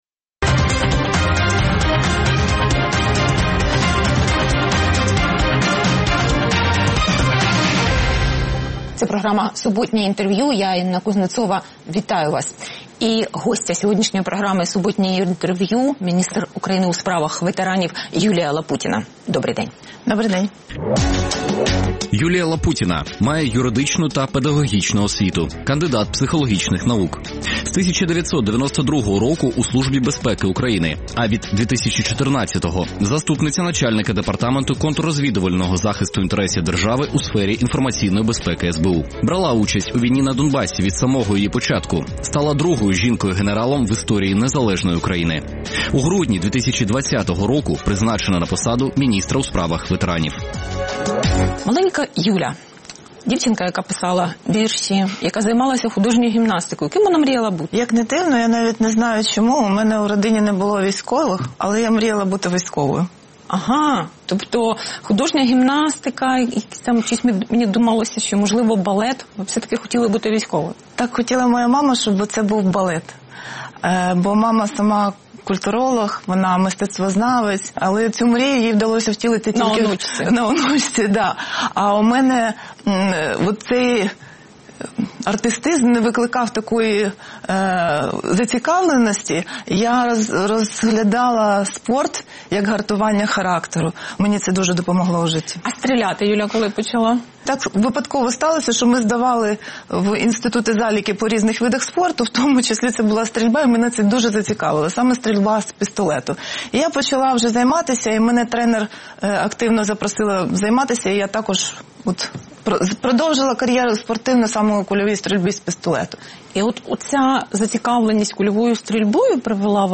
Суботнє інтерв’ю | Юлія Лапутіна, міністр у справах ветеранів України